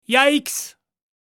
Yaiks Sound Effect
Add a fun, surprised, or comedic audio moment to videos, games, and animations. Perfect for enhancing playful or funny scenes.
Genres: Sound Effects
Yaiks-sound-effect.mp3